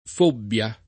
Fobbia [ f 1 bb L a ]